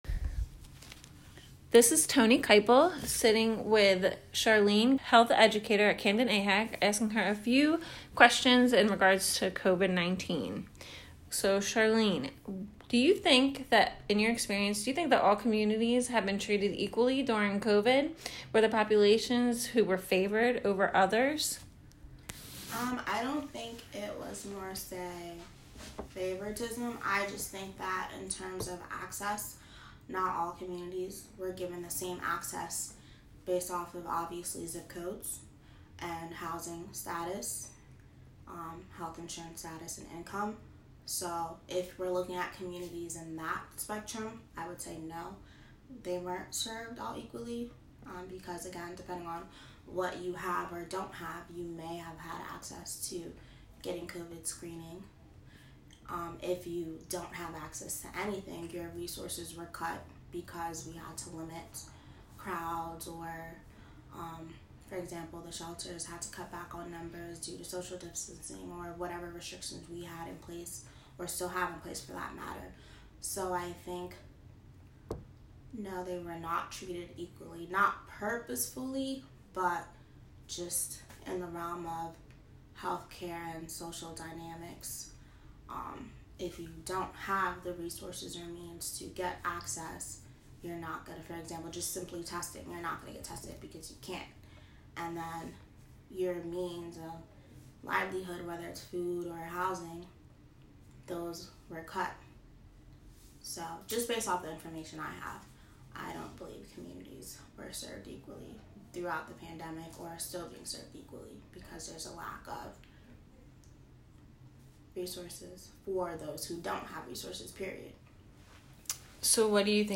Participant 133 Community Conversations Interview